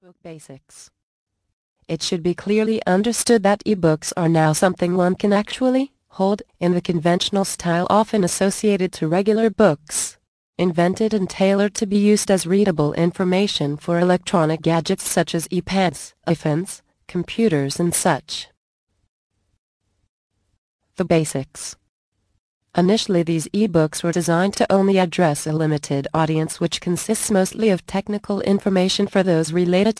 Ebook Publishing Secrets audio book + FREE Gift